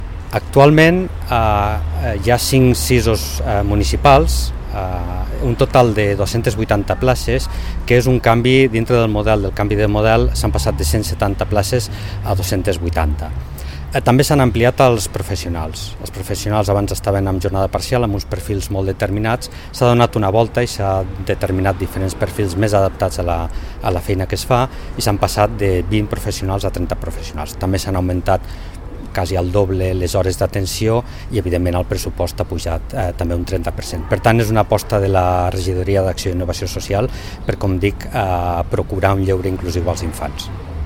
Tall de veu de l'alcalde accidental, Carlos Enjuanes, sobre el nou model d'atenció a la infància i l'adolescència i la visita que ha fet una de les activitats organitzades aquest estiu pels SIS municipals.